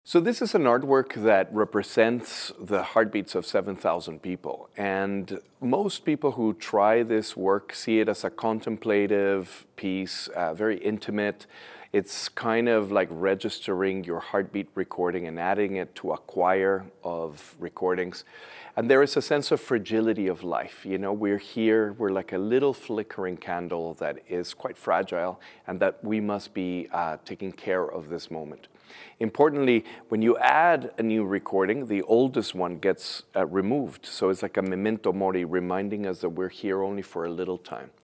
O-Ton Medienkünstler Rafael Lozano-Hemmer